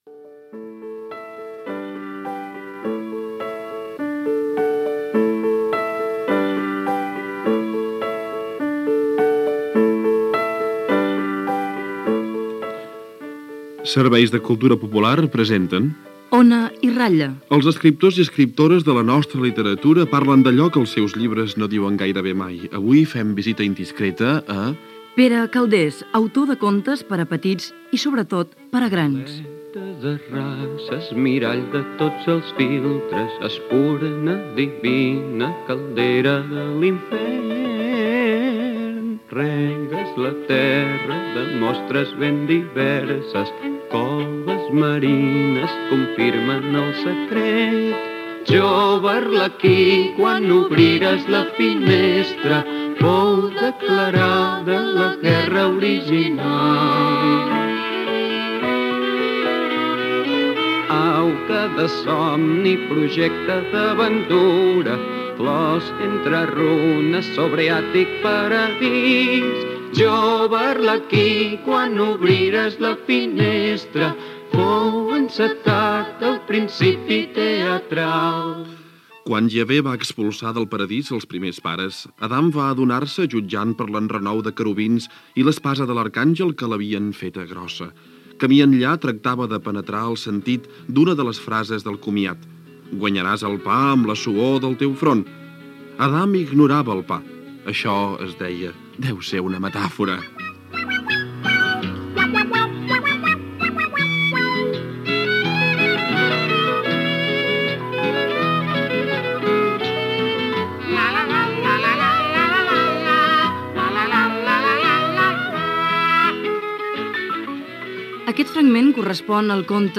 Careta del programa, fragment d'un conte de Pere Calders, dades biogràfiques de l'escriptor i entrevista